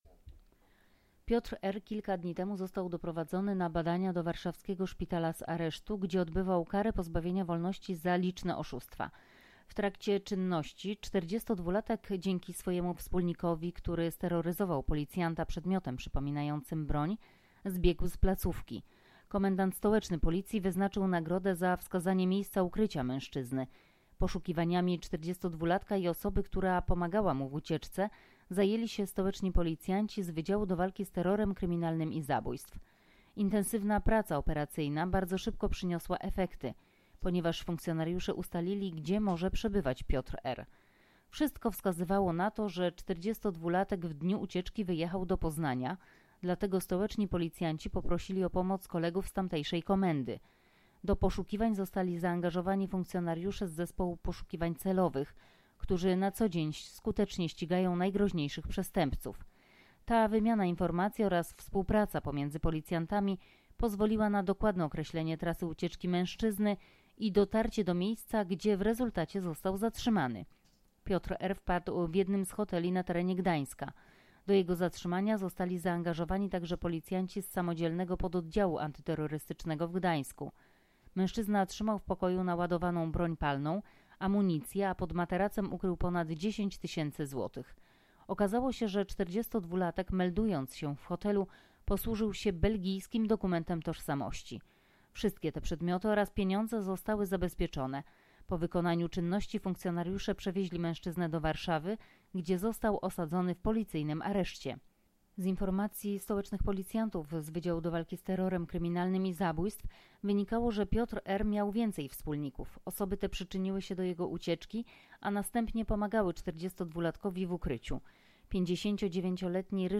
Nagranie audio Wypowiedź